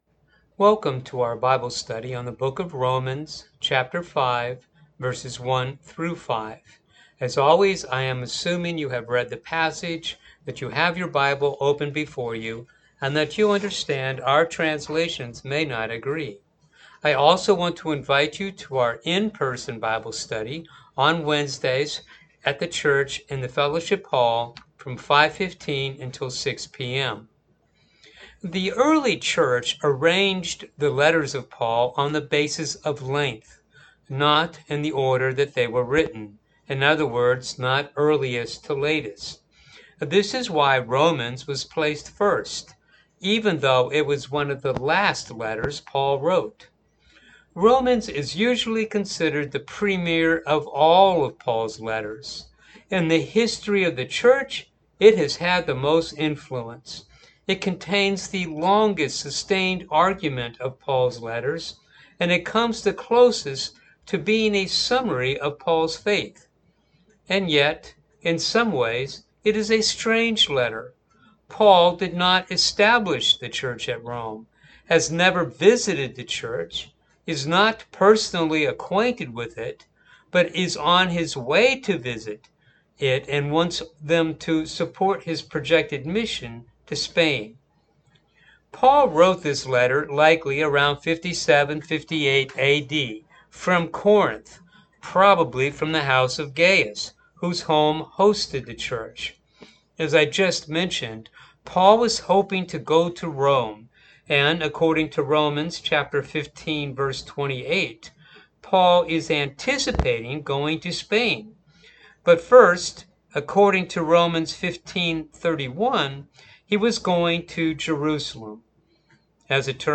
Bible Study for the June 12 Service